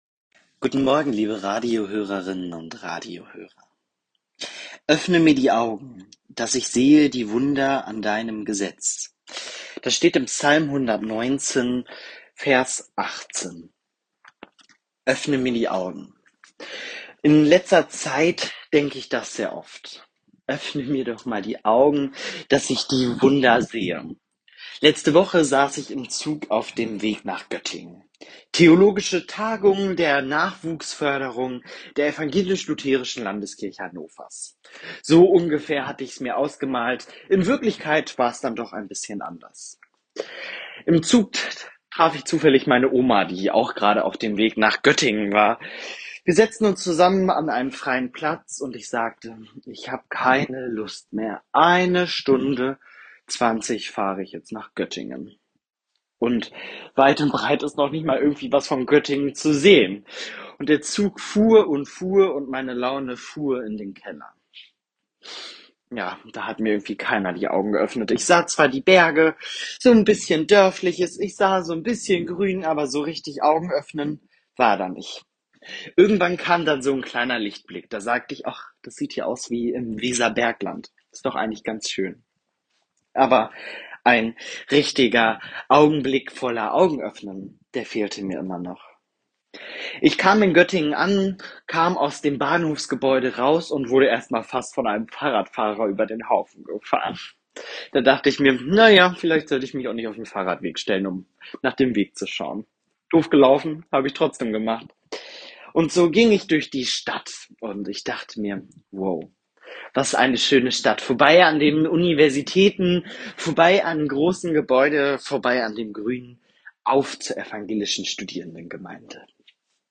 Radioandacht vom 4. September